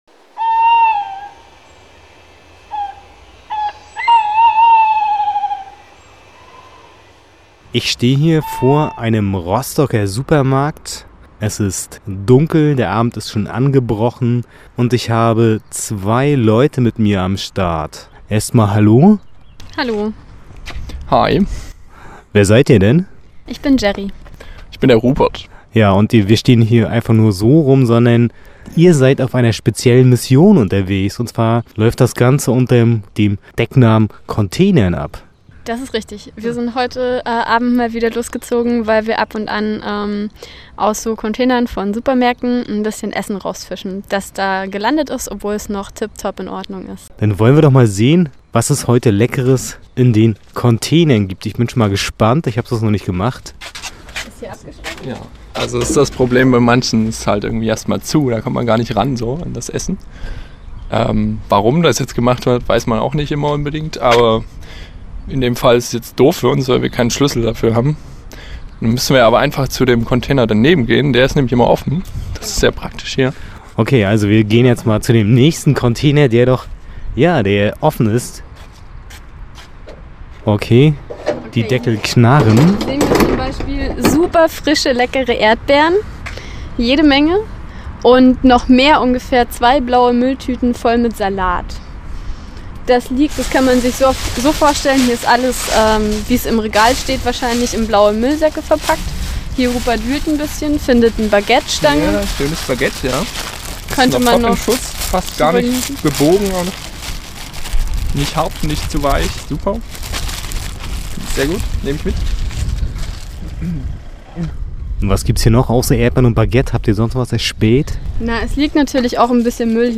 Da setzt das Containern an, wozu es hier eine Reportage zu hören gibt: